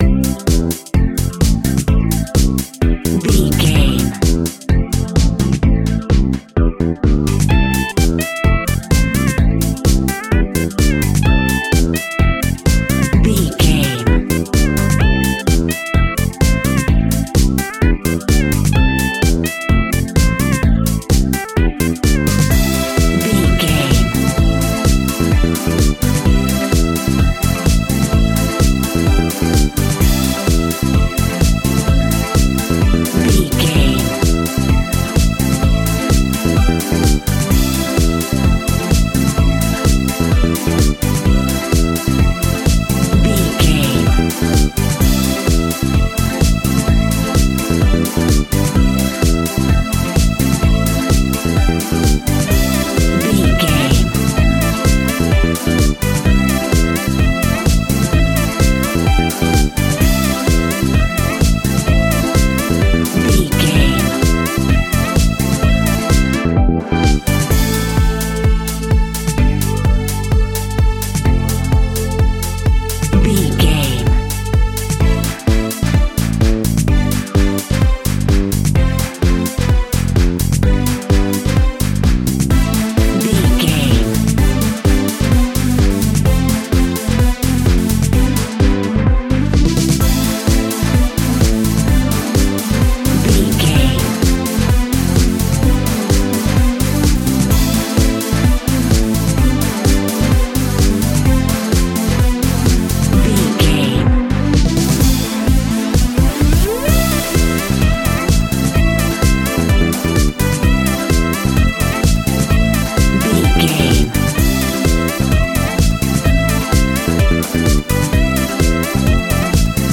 Funky House Sweep.
Aeolian/Minor
groovy
uplifting
bouncy
drum machine
synthesiser
bass guitar
upbeat
instrumentals